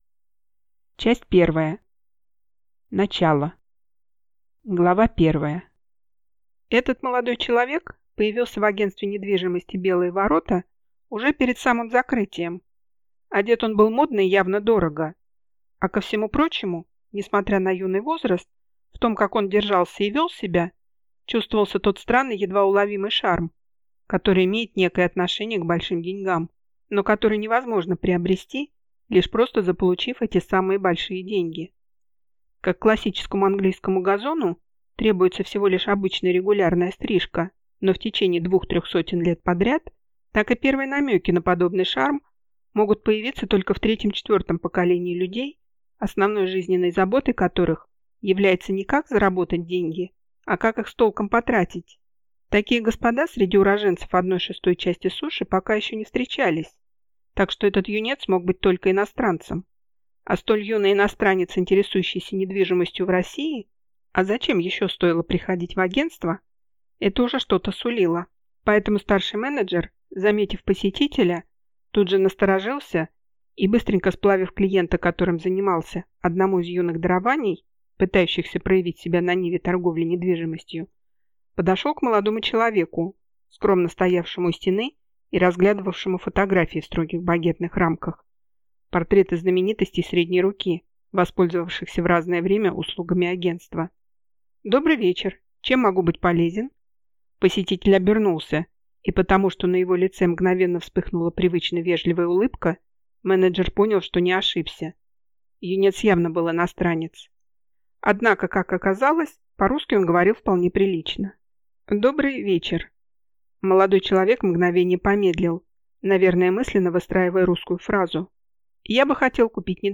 Аудиокнига Виват Император!